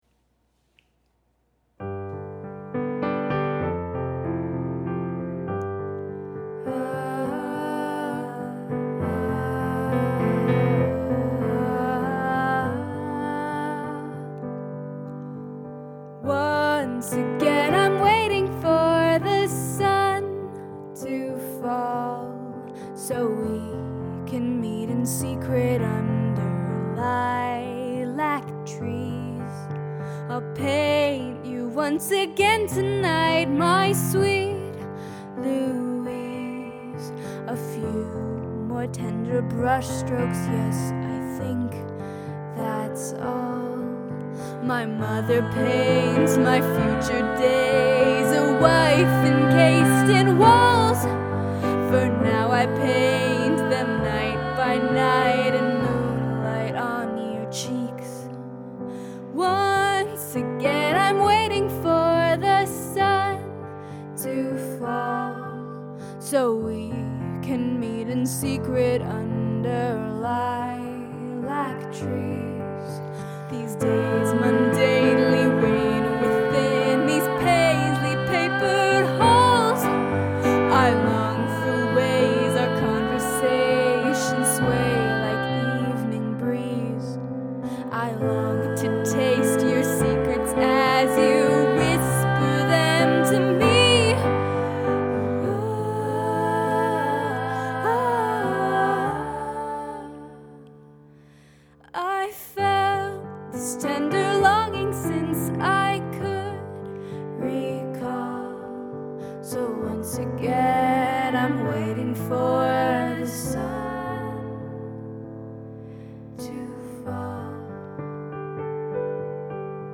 Rondel